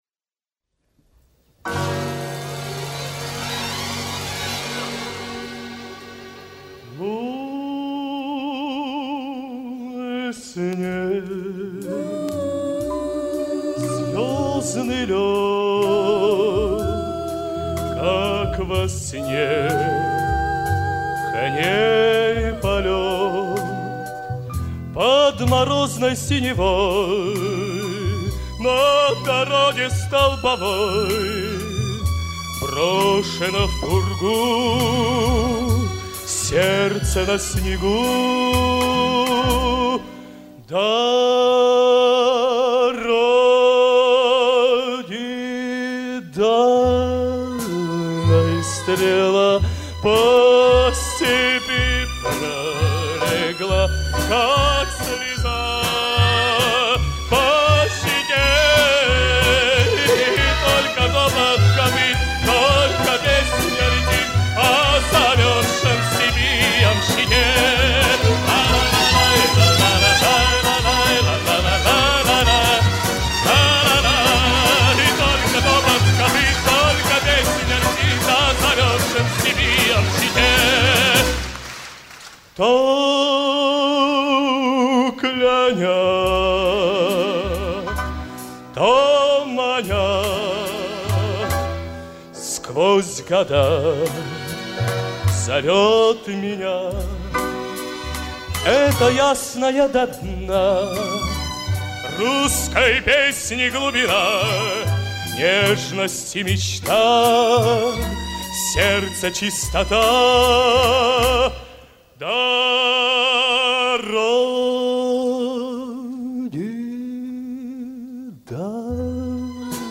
но это концертное исполнение просто феноменально